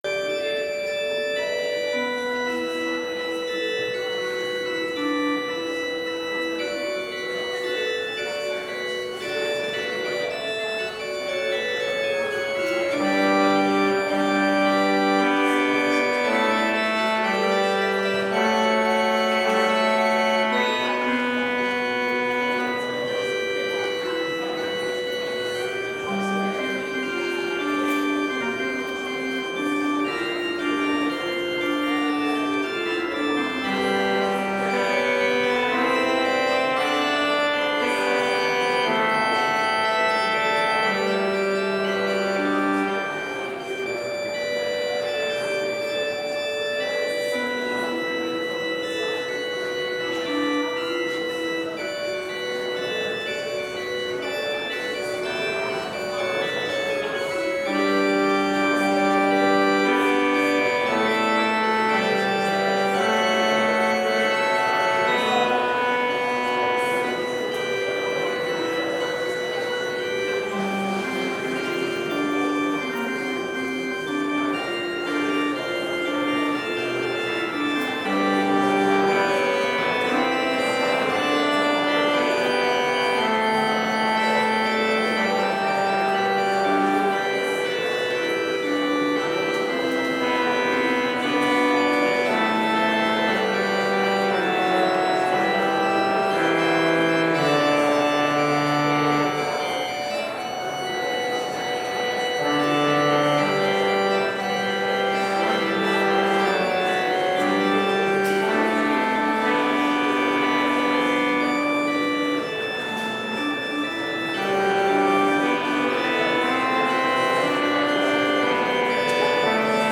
Complete service audio for Chapel - January 31, 2022